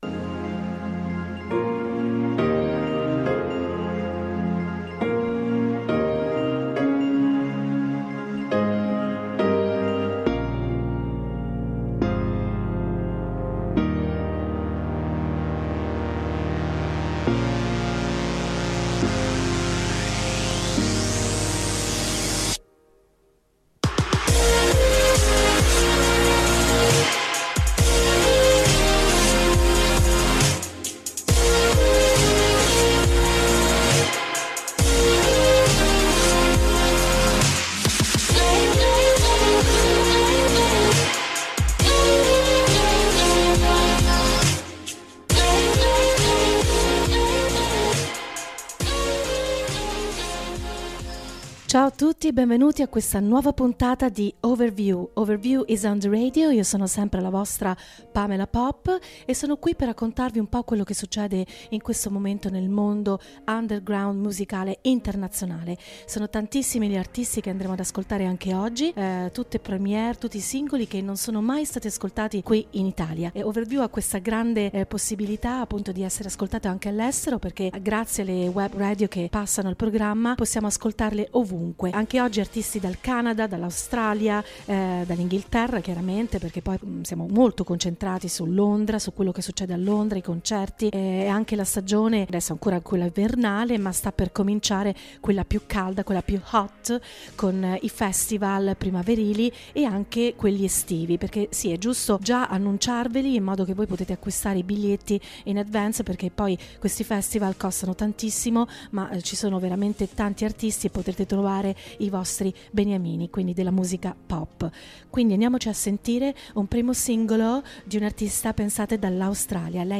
L’appuntamento radiofonico con Overview, rappresenta una panoramica sulle novità musicali e sugli artisti made in London, e non solo,condotto da